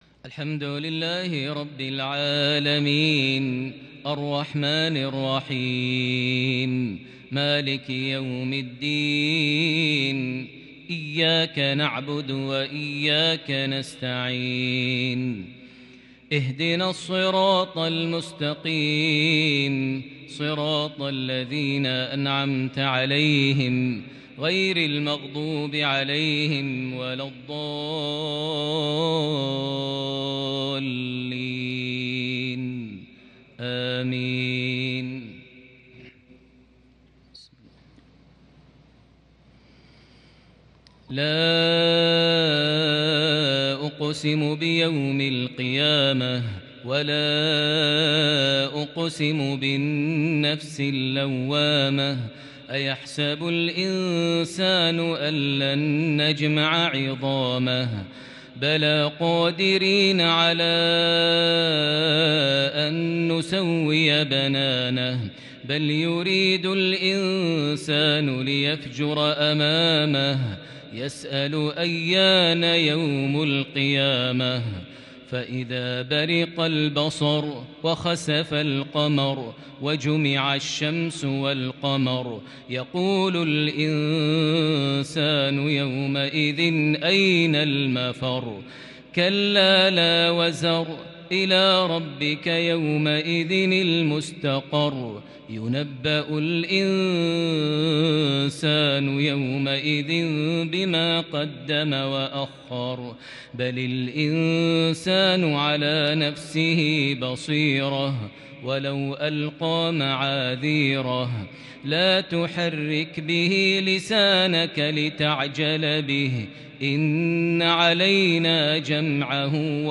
تلاوة بديعة للغاية لسورتي القيامة - البلد عشاء ٢٤ شوال ١٤٤١هـ > 1441 هـ > الفروض - تلاوات ماهر المعيقلي